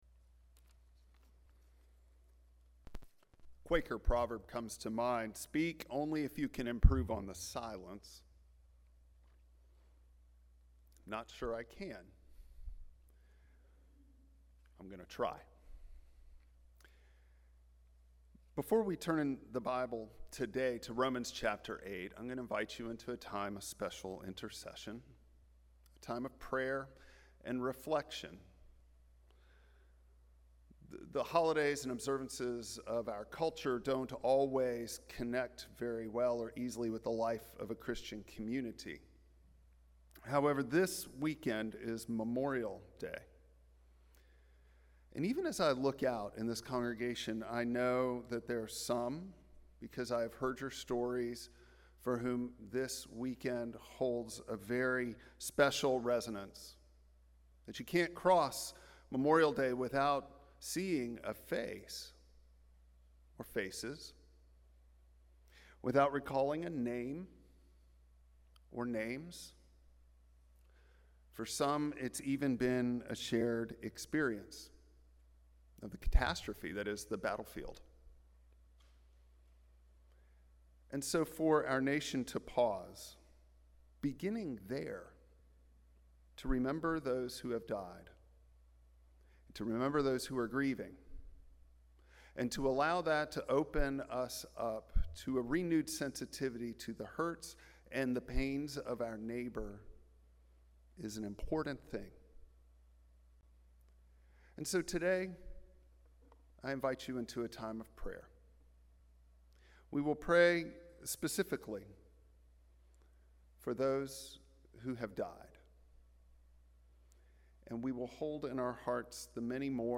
Passage: Romans 8:12-21 Service Type: Traditional Service